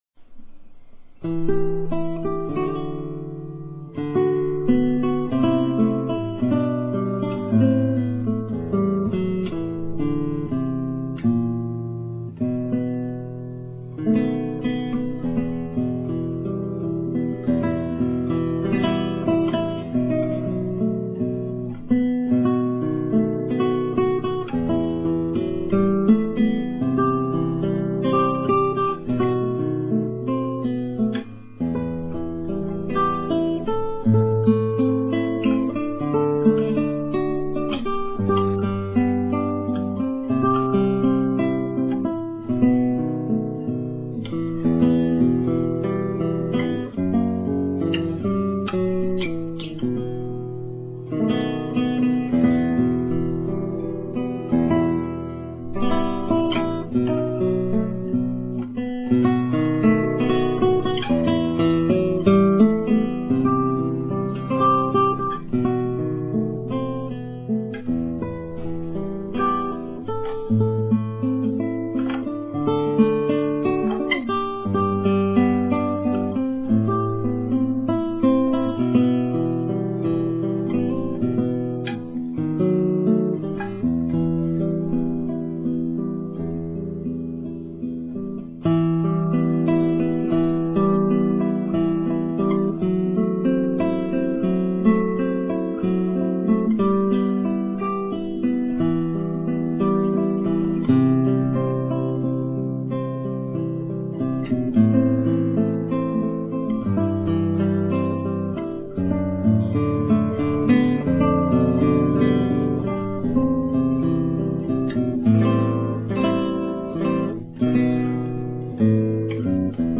Chuyển soạn cho Guitar